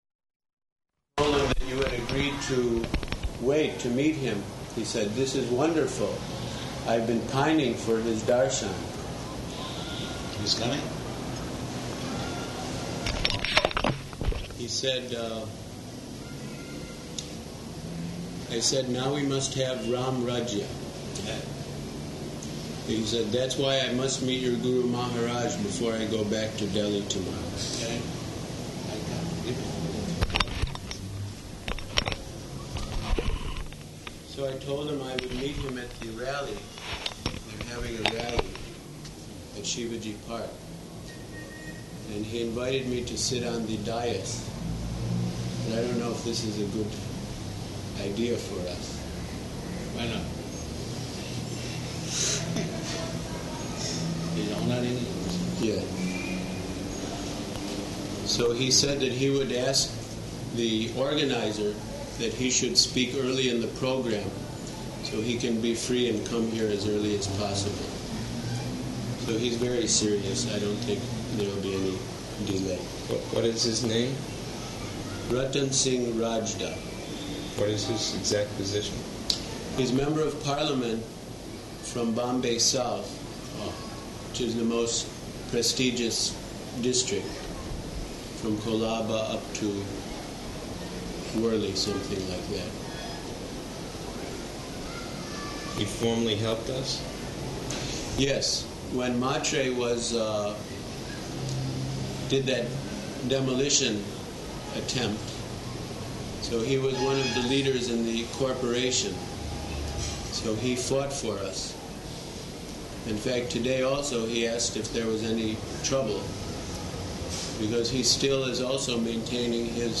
Type: Conversation
Location: Bombay